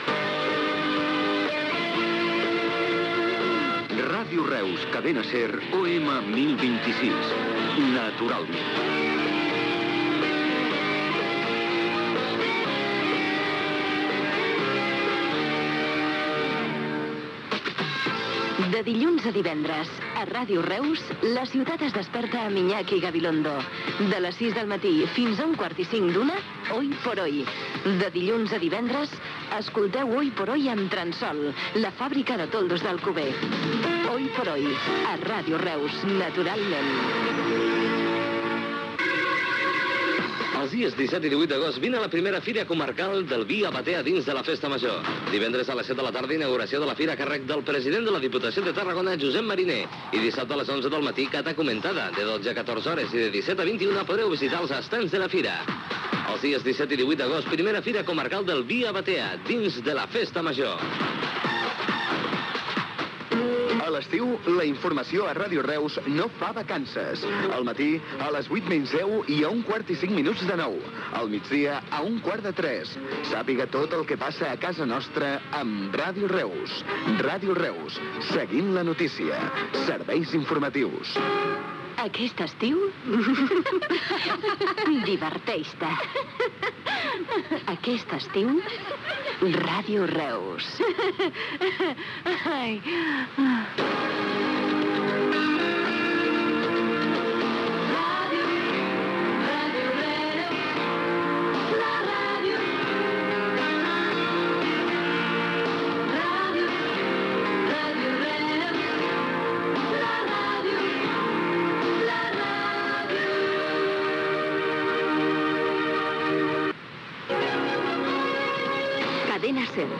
Identificació de l'emissora, promoció de "Hoy por hoy", publicitat, promoció dels serveis informatius, indicatiu de l'emissora.